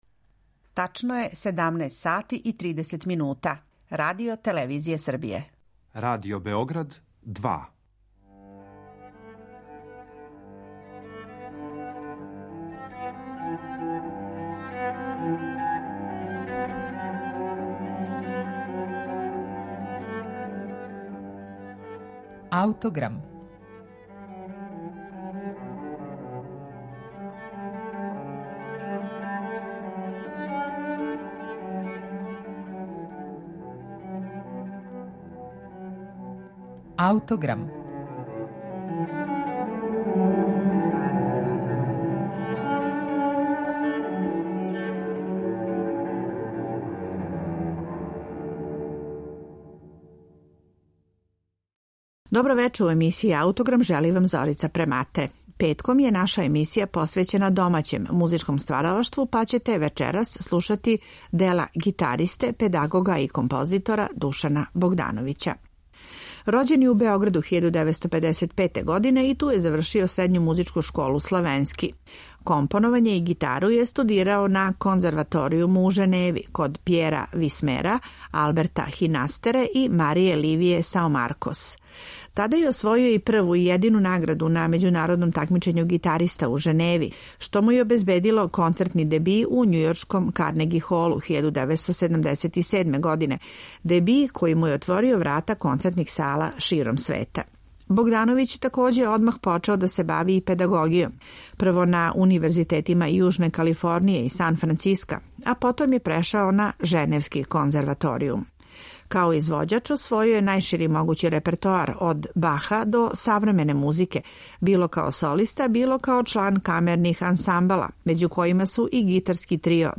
Настала је 1990. године, као балетска поема инспирисана поезијом Теда Хјуза, а за сопран, флауту, гитару и контрабас.